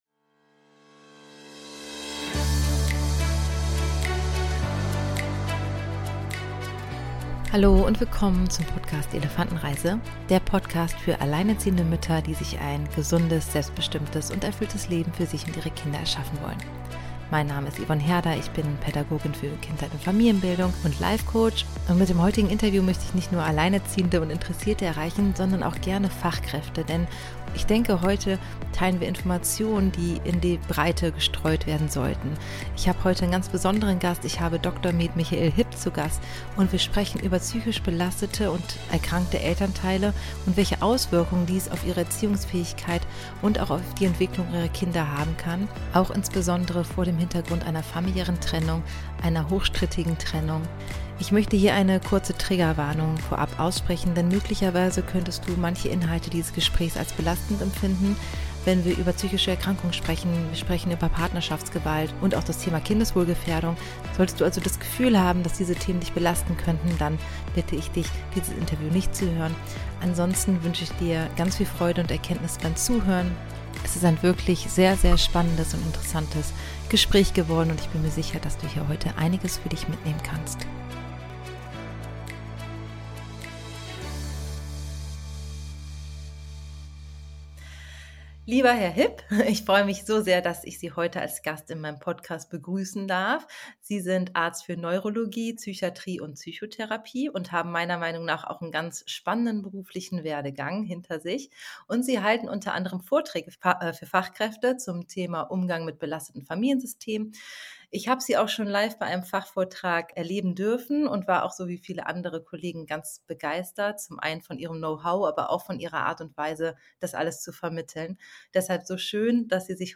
Wie finden wir auf die Elternebene? - Interview